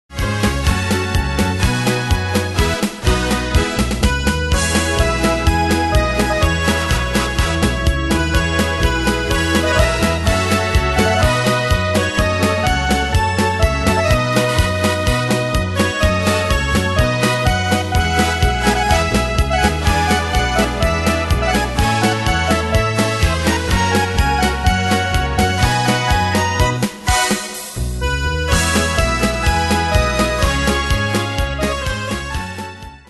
Style: Retro Ane/Year: 1938 Tempo: 125 Durée/Time: 3.25
Danse/Dance: Can-Can Cat Id.
Pro Backing Tracks